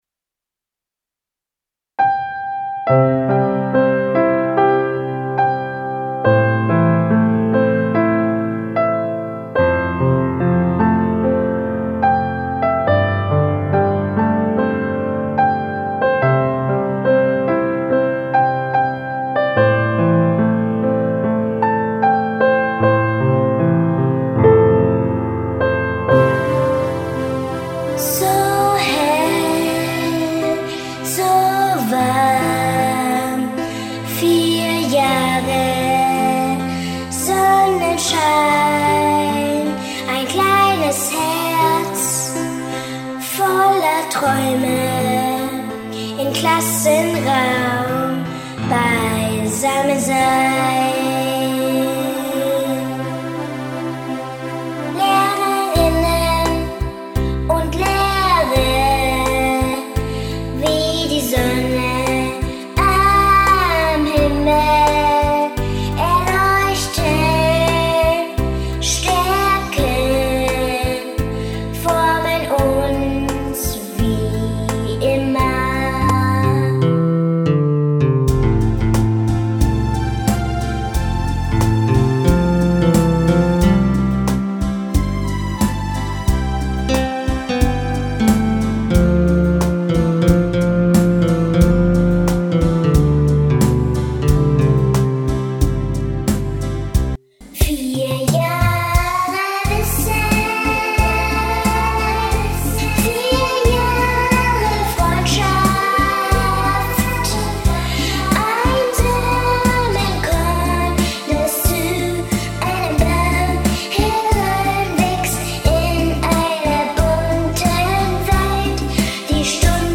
School song